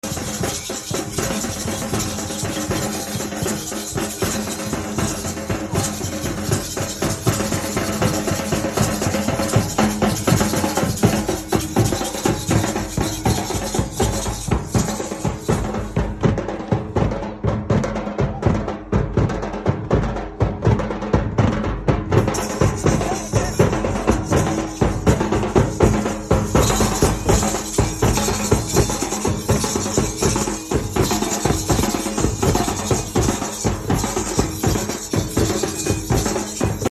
Nawabshah jalous 10 moharam night....❤ sound effects free download